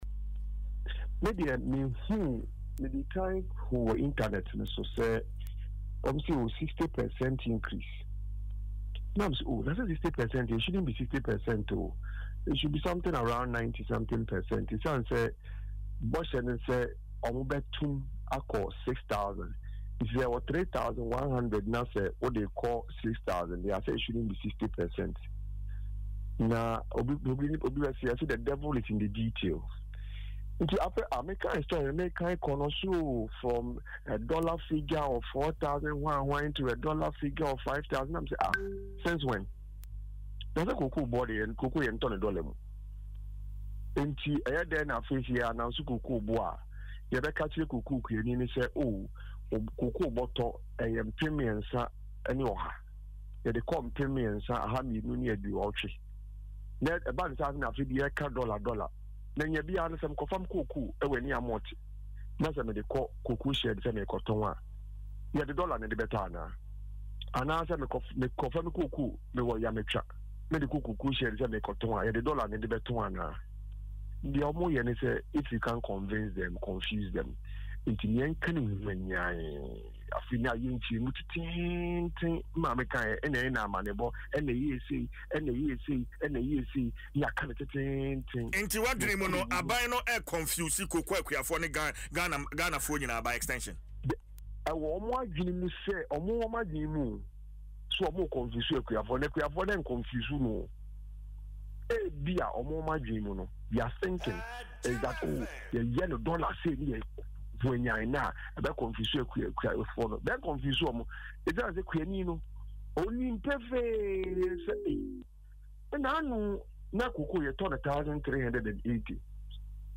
“I first saw it on the internet that it is a 60% increase, and I even thought it should be 90% given that they promised to pay GH₵6,000,” he said in an interview on Adom FM’s Dwaso Nsem.